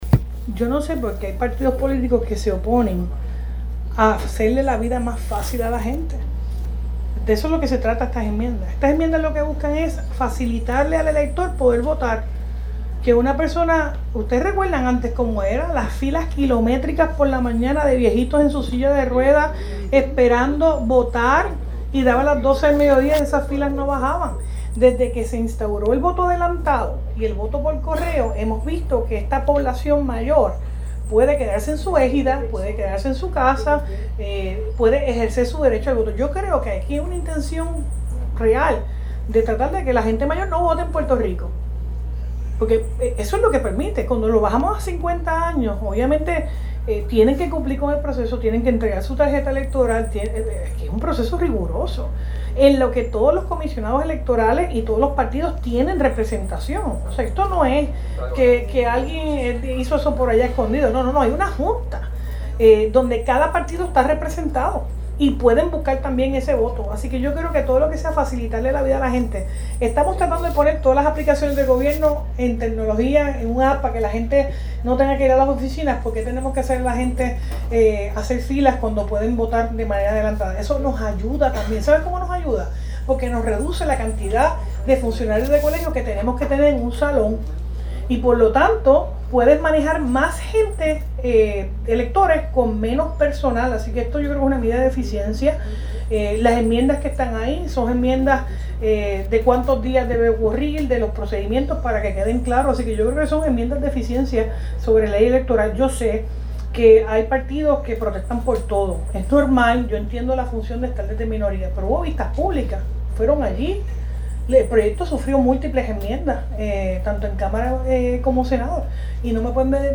Gobernadora defiende recien aprobada ley que enmienda el código electoral (sonido)